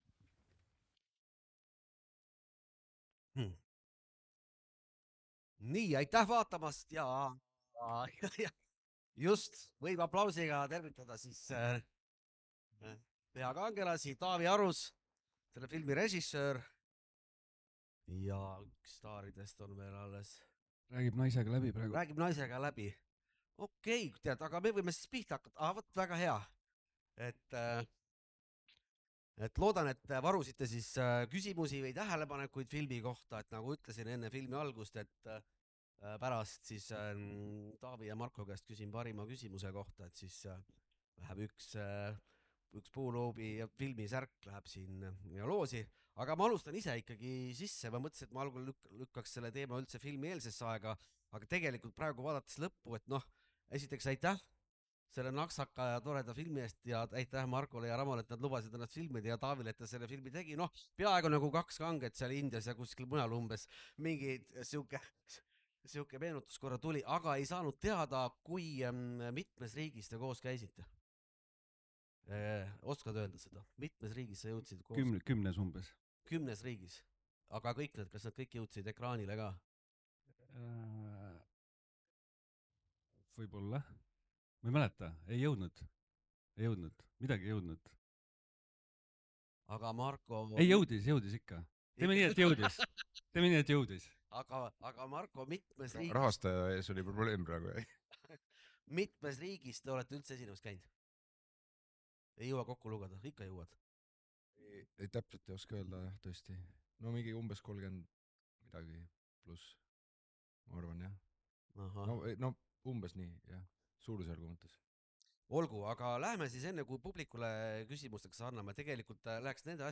Jagus naeru, mõtteid ja lugusid. Publik kuulas ning küsis kaasa. Siin saad kuulata õhtu esimest vestlust.